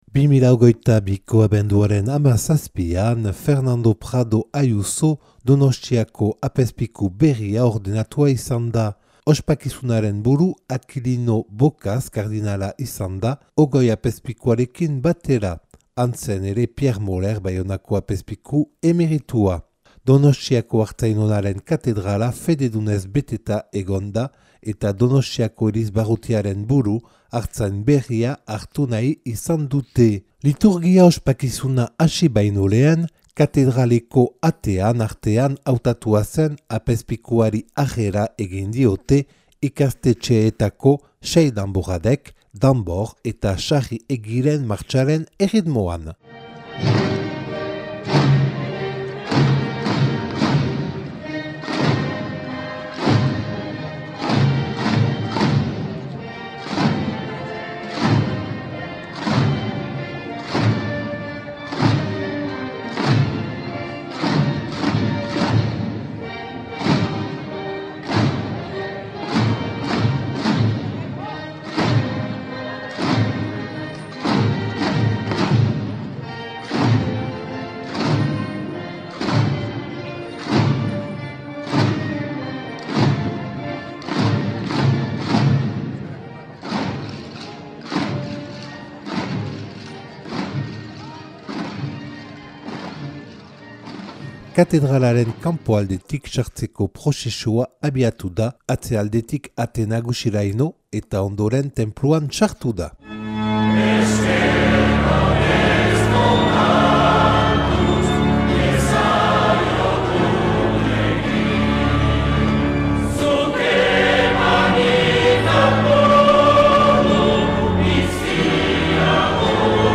2022. abenduaren 17an Fernando Prado Ayuso Donostiako apezpikua ordenatua izan da. Huna elizkizunaren bilduma bat.